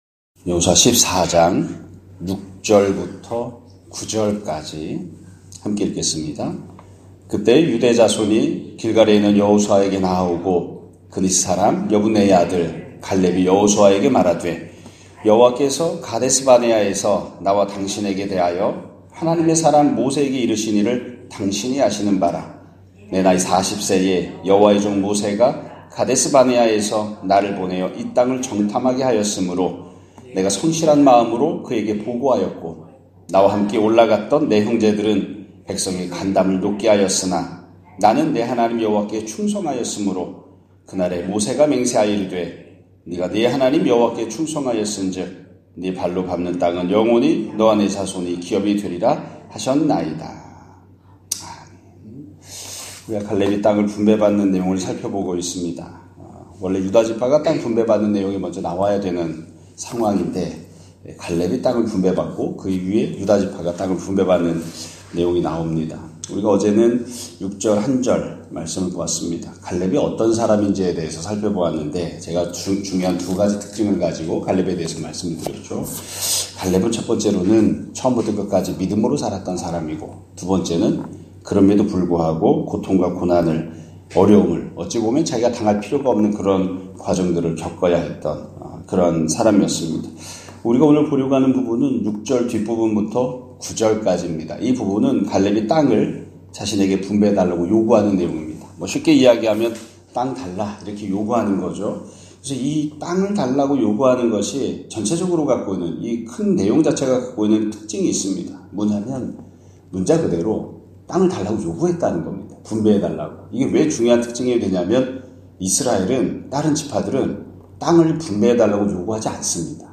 2024년 11월 27일(수요일) <아침예배> 설교입니다.